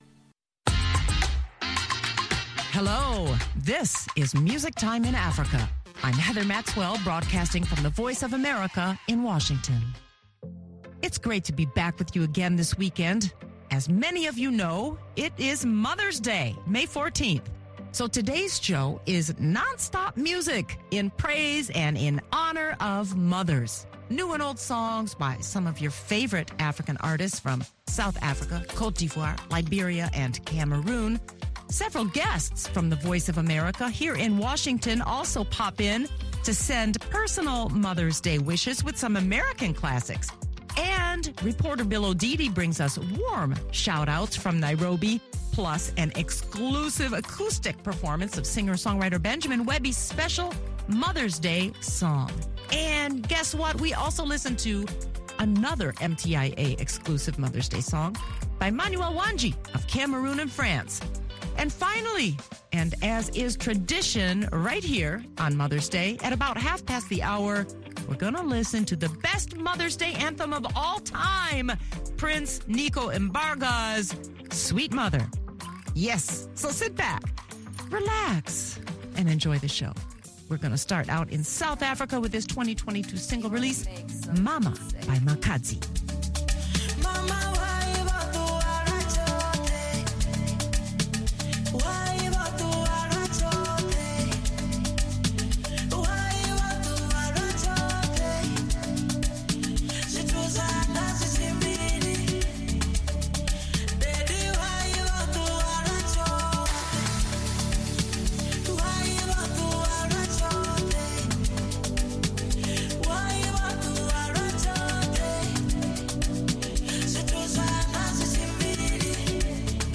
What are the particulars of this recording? exclusive, live studio performance from Nairobi